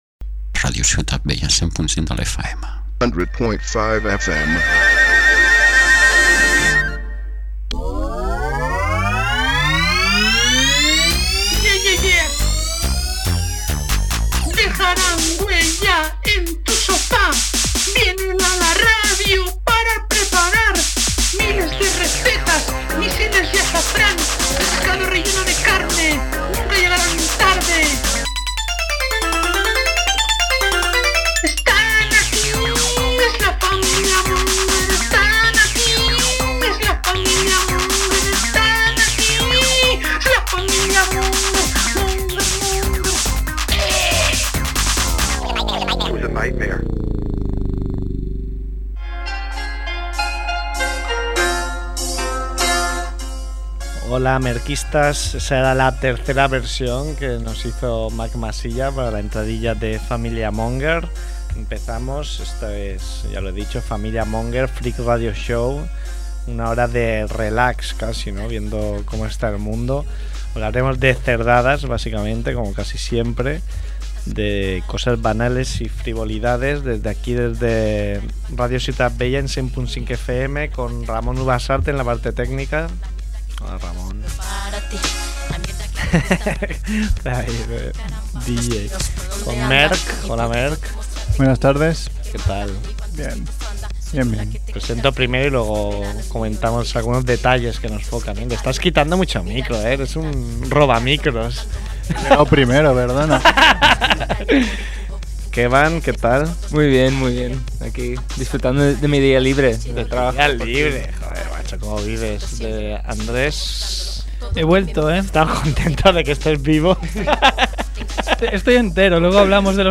Programa rodado en el que nos visita el cómico y monologuista